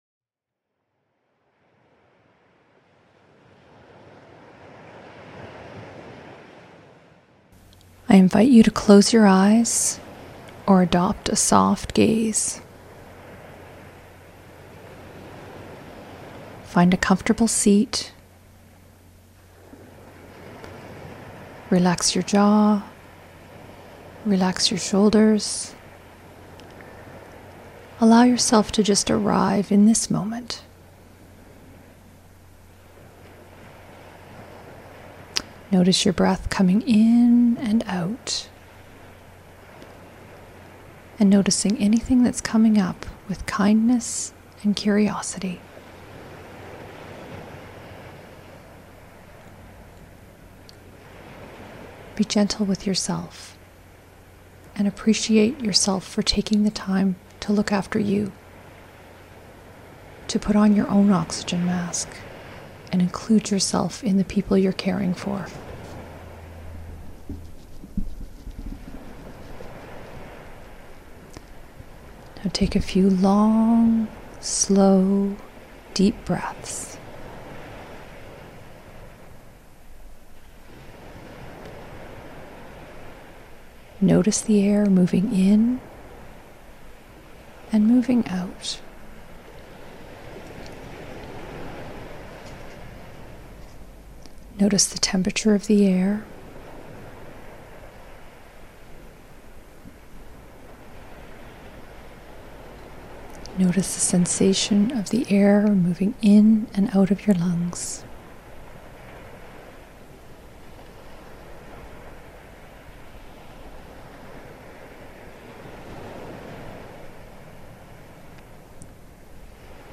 CIC_Meditation_Week_2.mp3